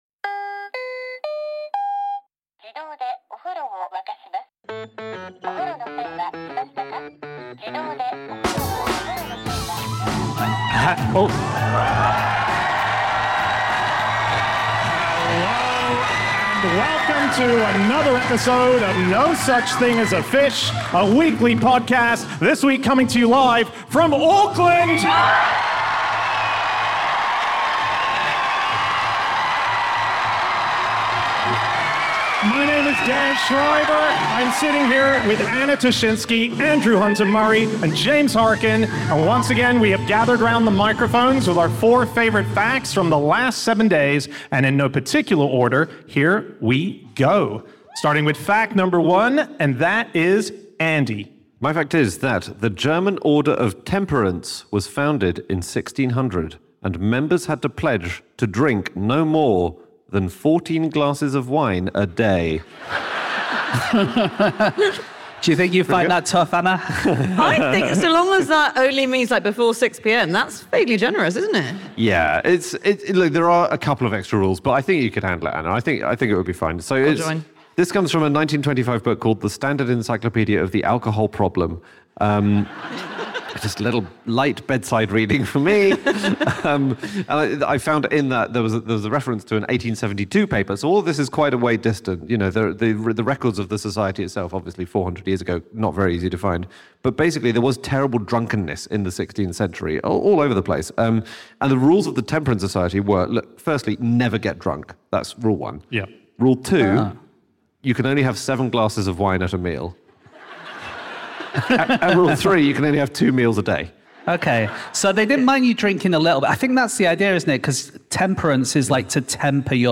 Live from Auckland